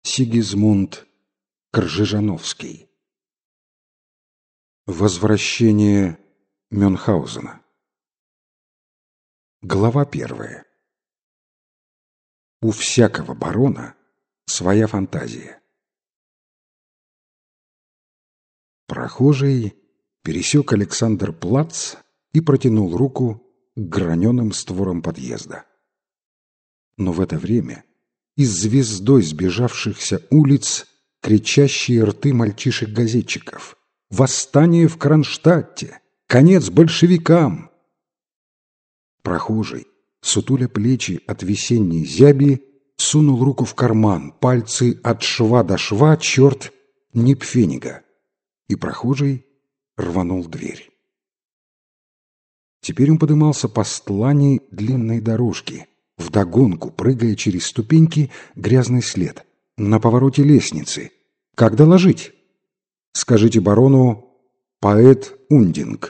Аудиокнига Возвращение Мюнхгаузена | Библиотека аудиокниг